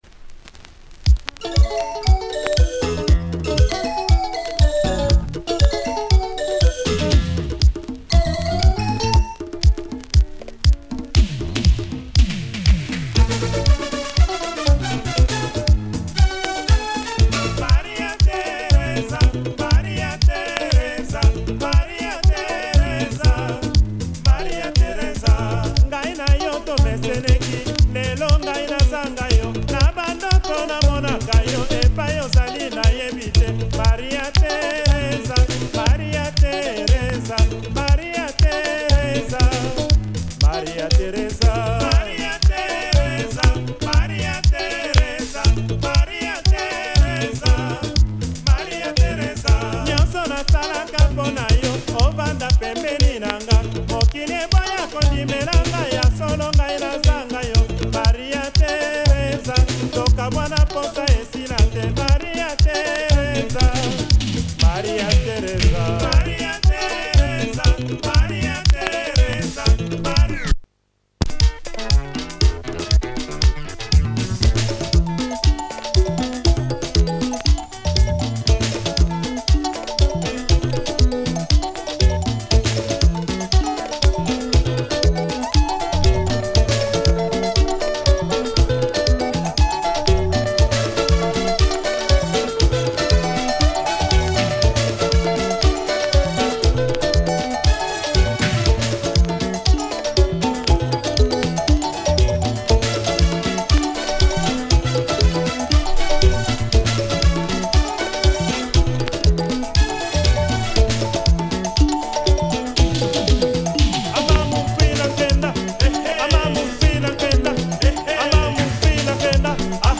80s synthesizer Lingala.